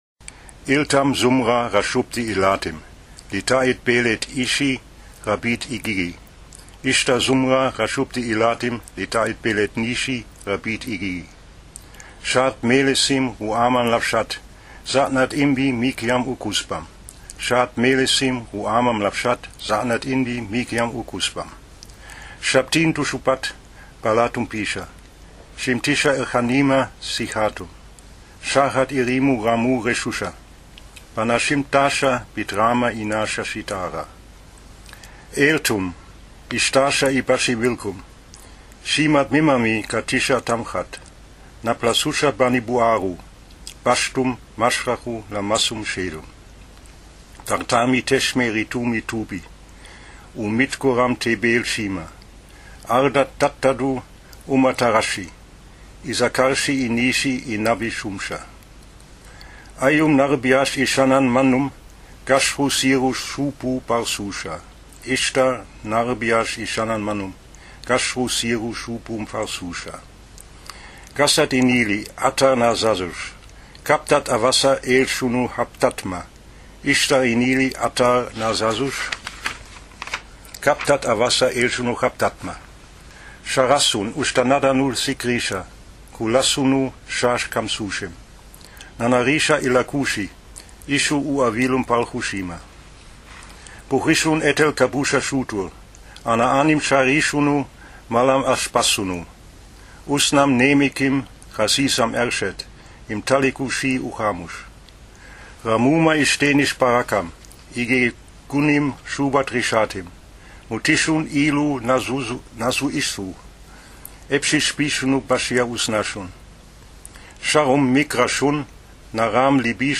[German 1]